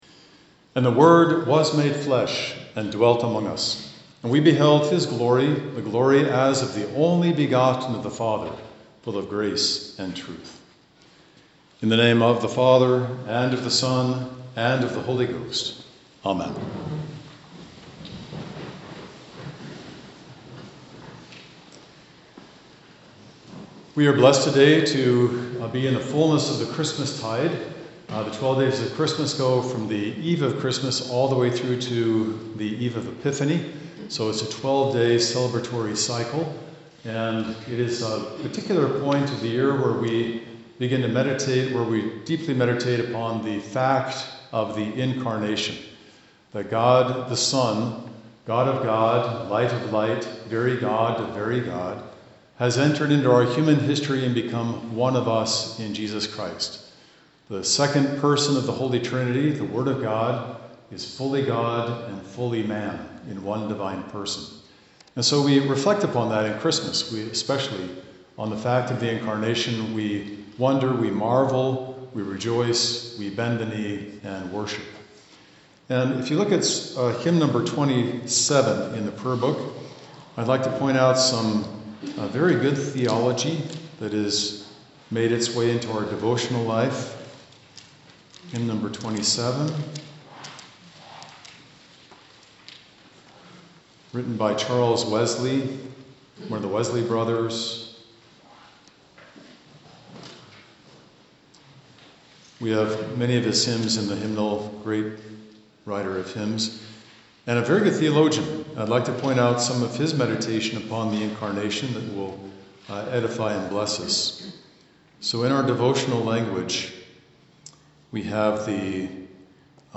Sermon-for-II-Christmas-2026-1.mp3